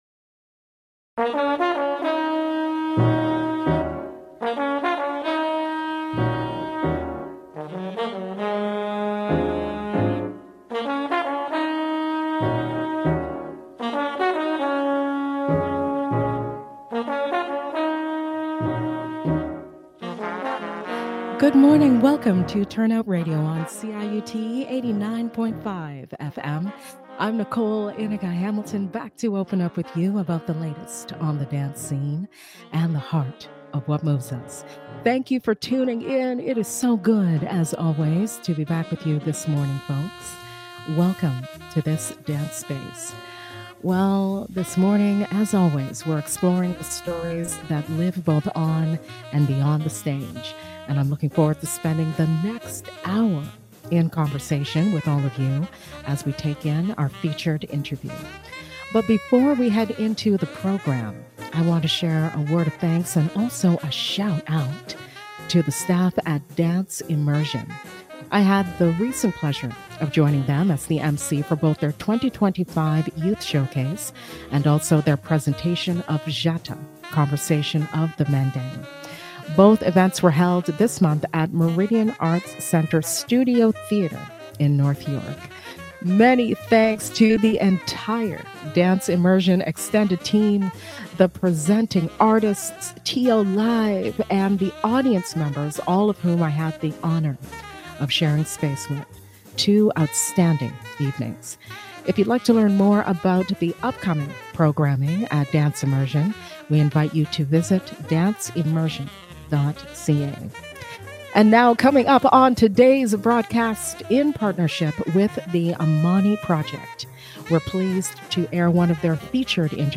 This 2022 interview from The AMANI Project archives, makes its debut on Turn Out Radio.